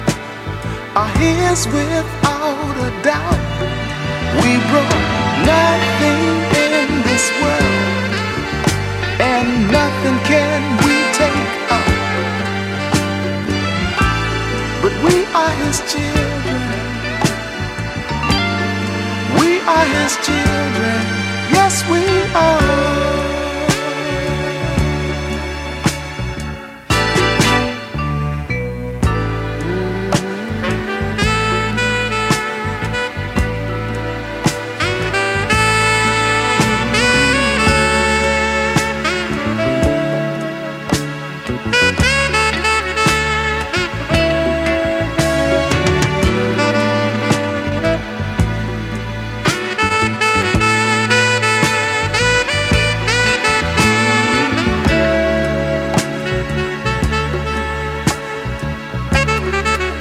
ジャンル(スタイル) SOUL / FUNK